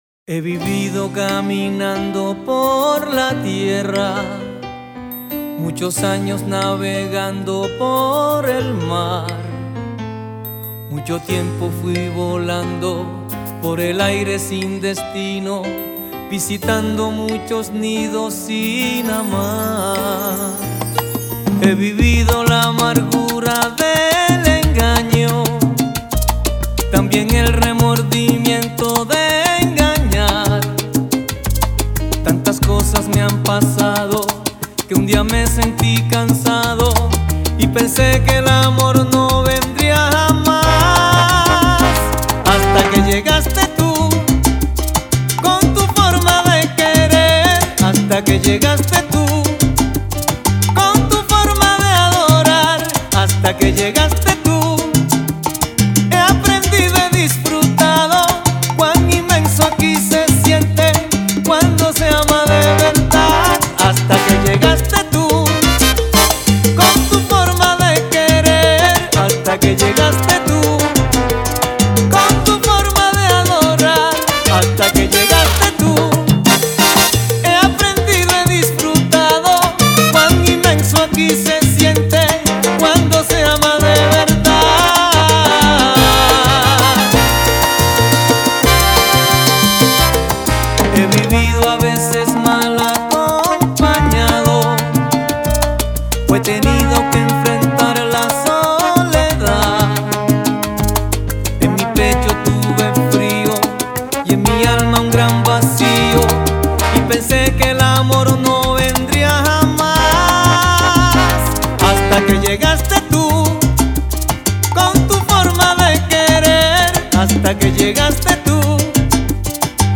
Немного ностальгической сальса-романтики.